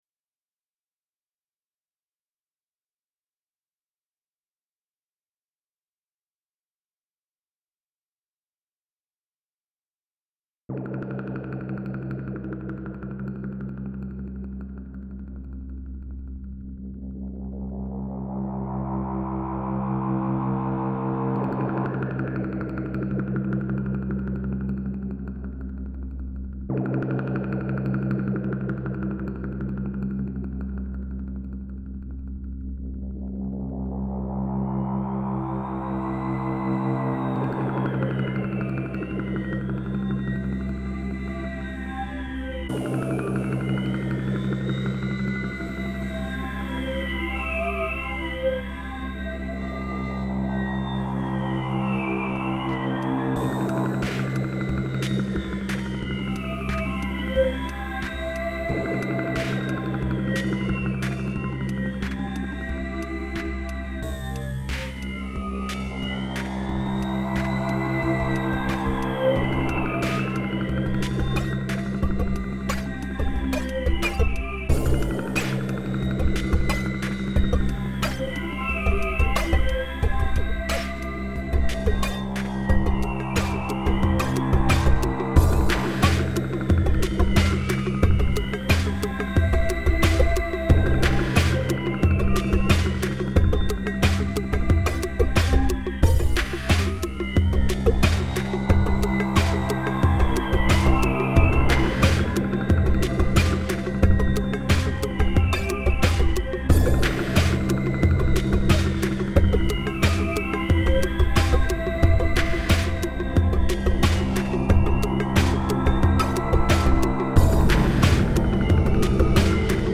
Spent some time fiddling around with Ableton Live and a MIDI controller tonight. Just noodling.